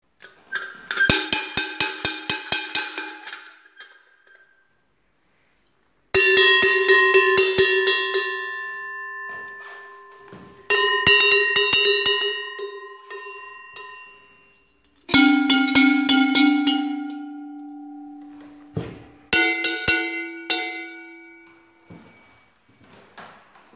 Kategorien Soundeffekte